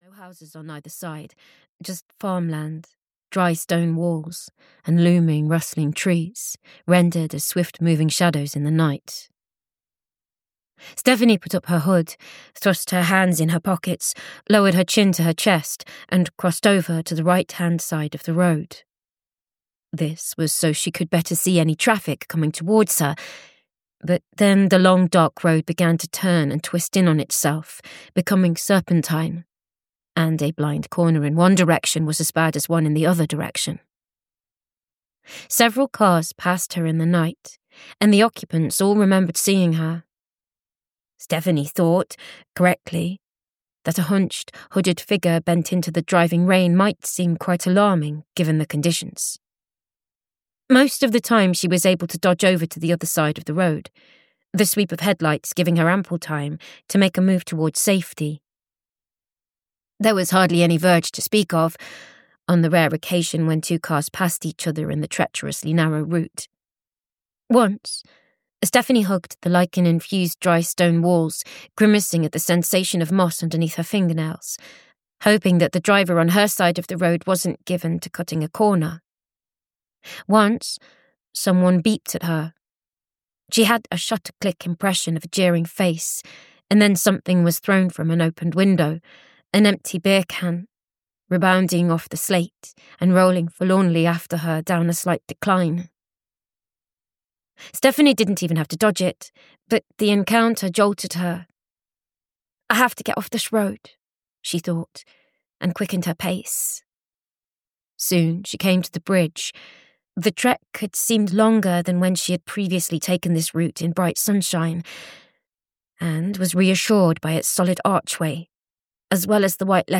The Long Dark Road (EN) audiokniha
Ukázka z knihy